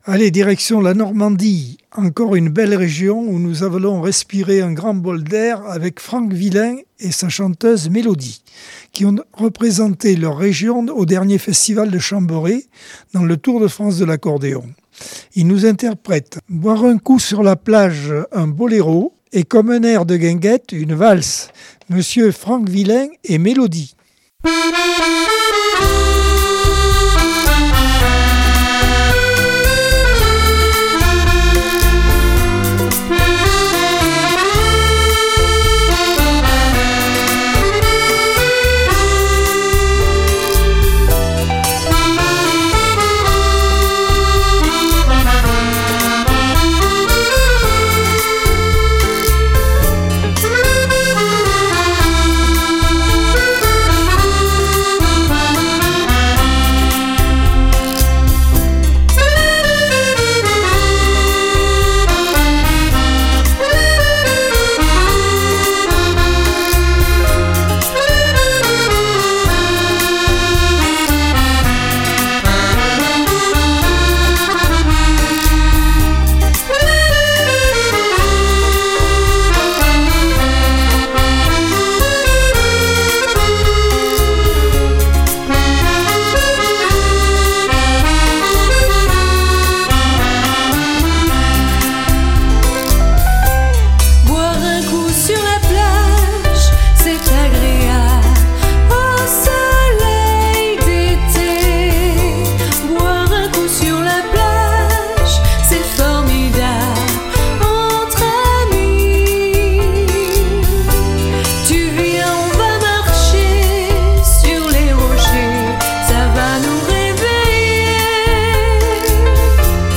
Accordeon 2026 sem 03 bloc 2.